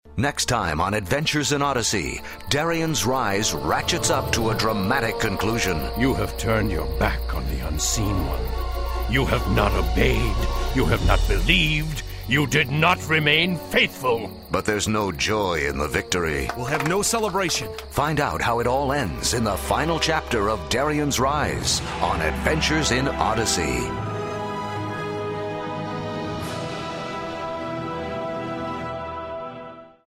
Notes: This audio dramatization is based on Darien's Rise from the Adventures in Odyssey Passages book series.